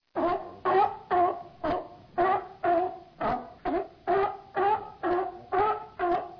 Som do Cavalo-Marinho -
Cavalo-Marinho
Cavalo_marinho.mp3